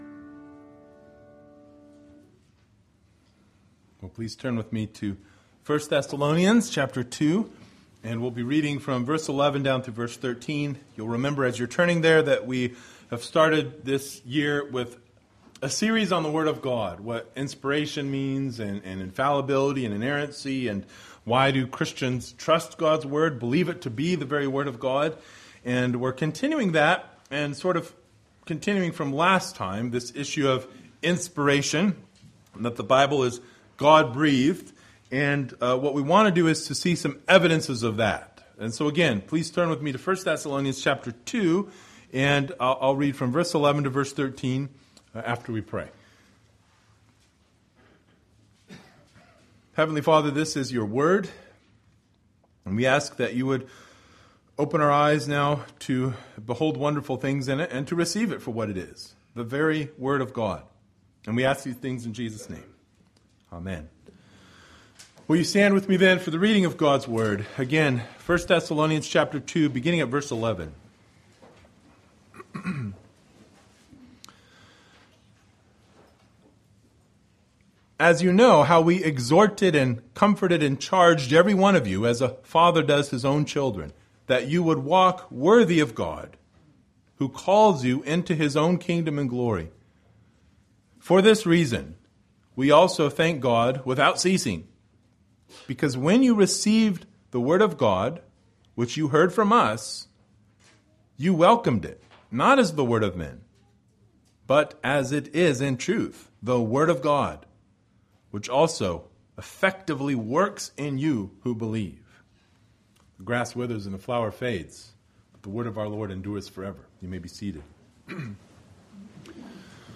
Passage: I Thessalonians 2:11-13 Service Type: Sunday Morning